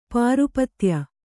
♪ pārupatya